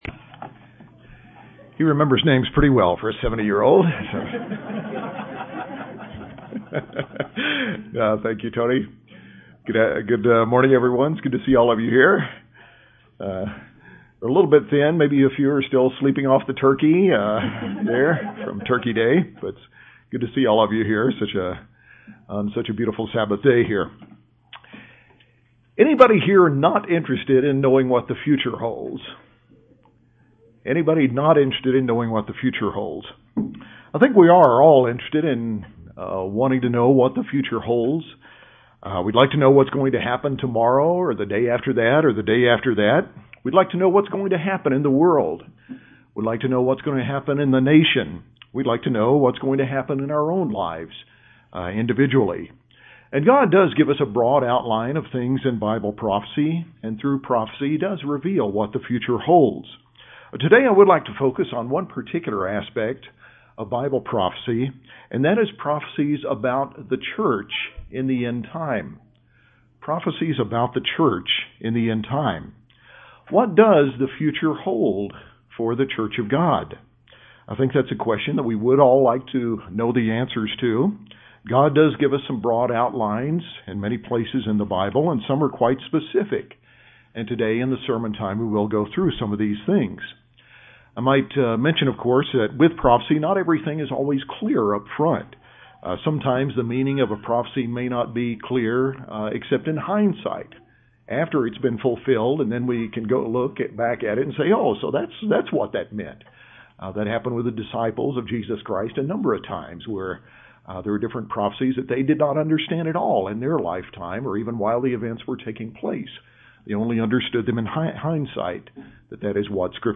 This sermon examines six prophecies of trends that will effect the Church, several of which are affecting us now or have affected the Church in recent years. All are messages of warning to the Church as the time approaches for his return.